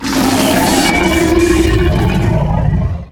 CosmicRageSounds / ogg / general / combat / enemy / droid / bighurt2.ogg
bighurt2.ogg